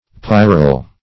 pyral - definition of pyral - synonyms, pronunciation, spelling from Free Dictionary Search Result for " pyral" : The Collaborative International Dictionary of English v.0.48: Pyral \Py"ral\, a. Of or pertaining to a pyre.